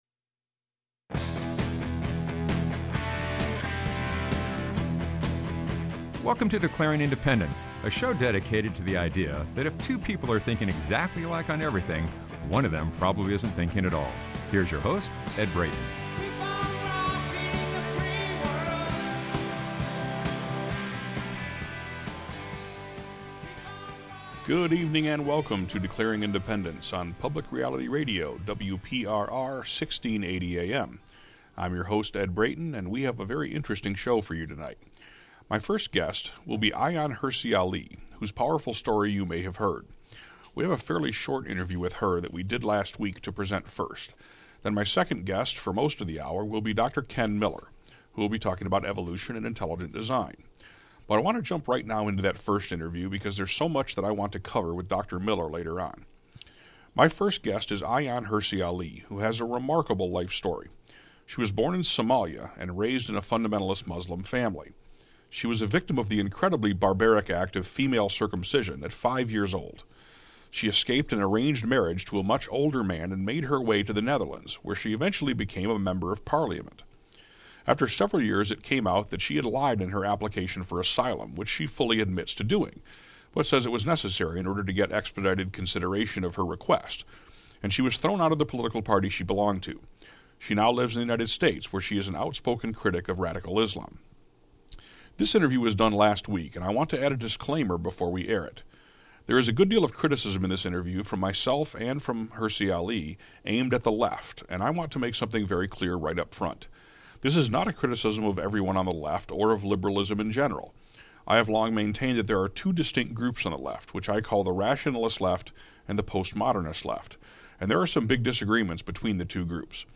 [COMMENTARY] On last week’s Declaring Independence I had the privilege of doing a brief interview of Ayaan Hirsi Ali, the extraordinarily brave Somali-born woman who works diligently to fight against the barbaric practices of radical Islam.